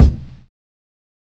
HFMKick5.wav